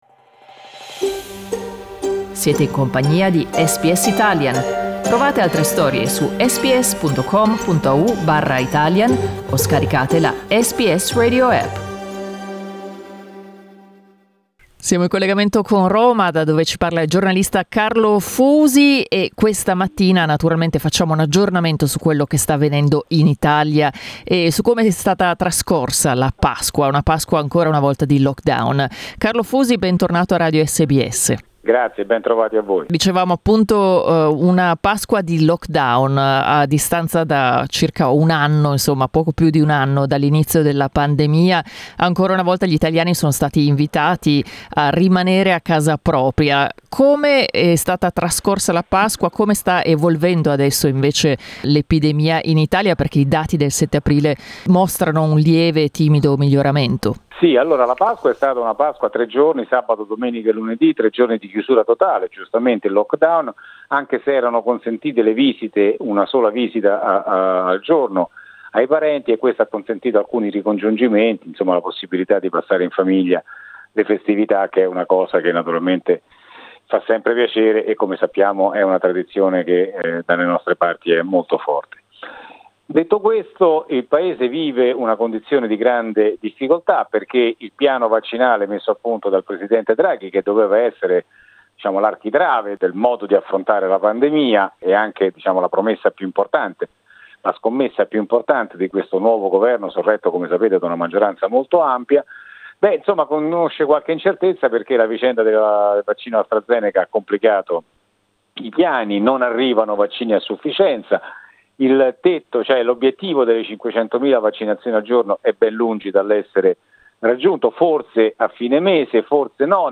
abbiamo parlato con il giornalista di Roma